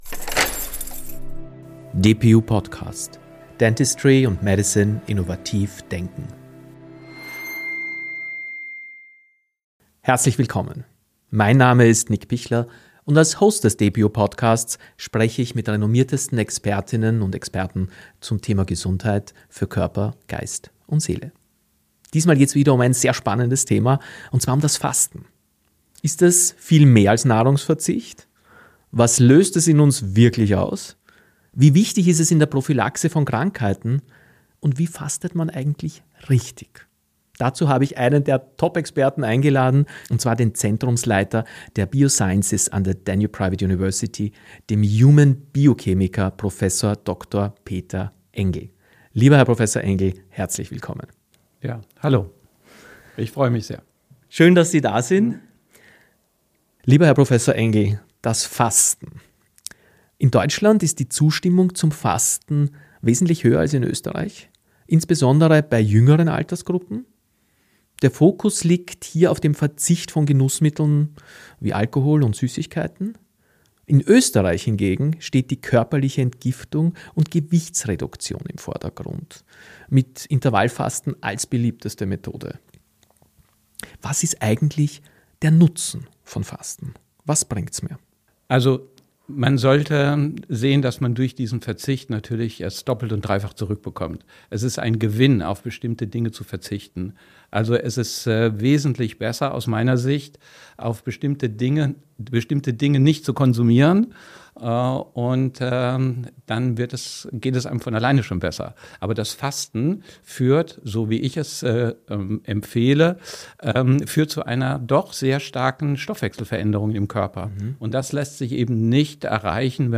Ein Gespräch über gesunde Gewohnheiten, nachhaltige Veränderungen und die überraschenden Potenziale des Fastens für Körper Geist und Seele.